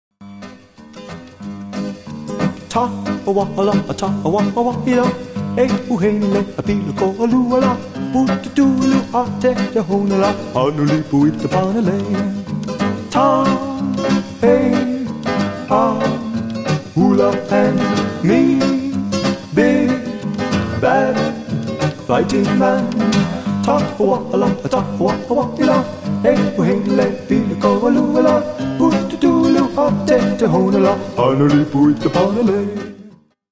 DIGITALLY RE-MASTERED FOR BETTER SOUND!